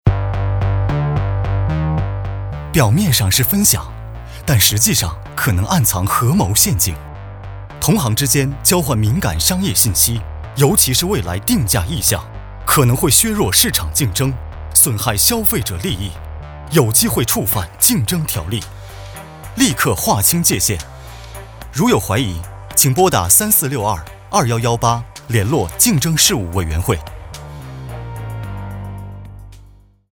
电视及电台广告 电视广告 电台广告 短剧 禁忌之密语 第一章 第二章 最终章 预告片 刊物 《交换资料》小册子 在行业协会会议上交换资料的实务指南 研讨会 详情将于稍后公布，敬请密切留意。